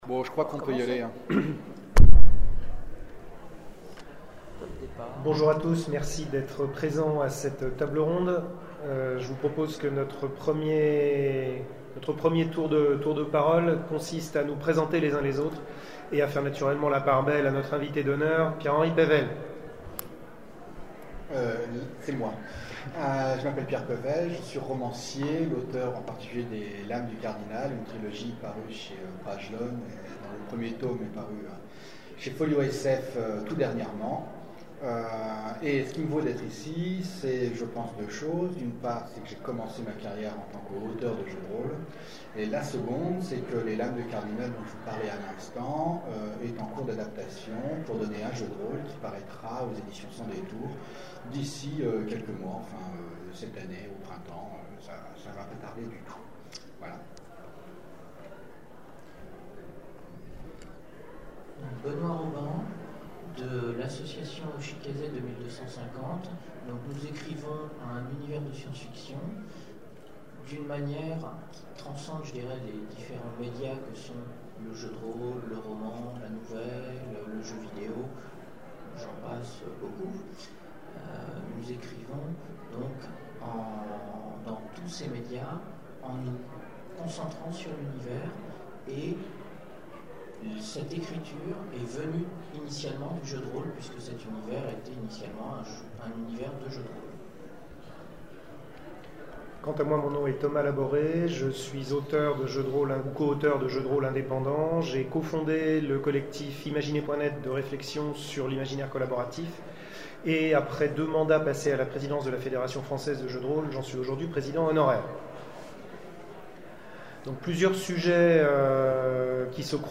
Zone Franche 2013 : Conférence L'adaptation d'un roman en jeu de rôle